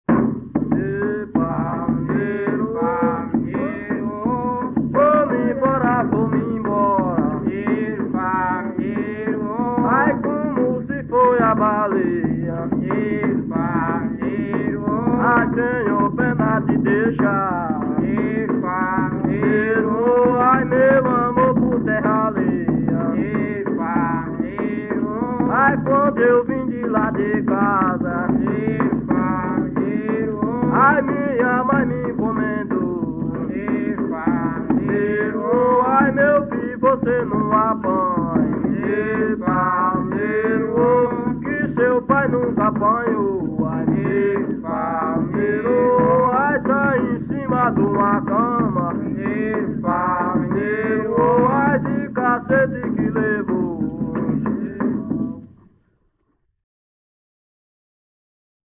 Coco -“”Mineiro pau”” - Acervos - Centro Cultural São Paulo